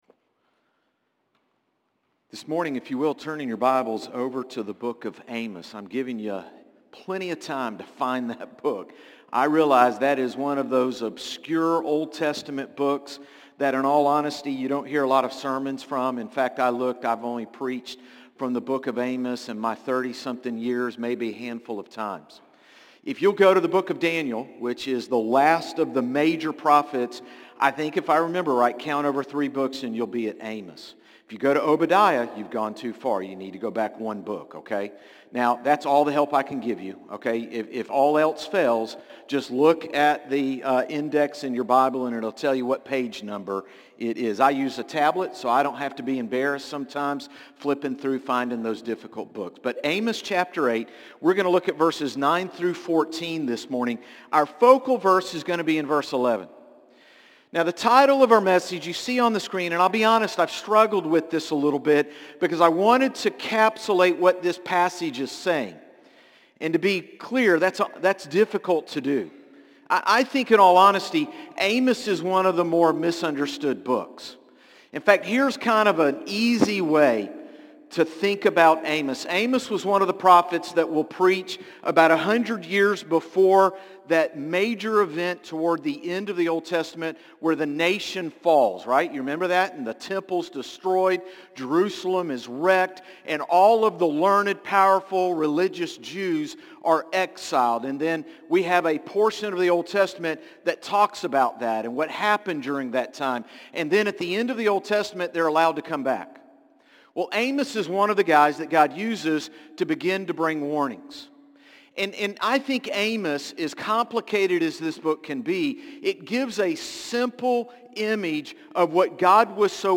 Sermons - Concord Baptist Church
Morning-Service-8-25-24.mp3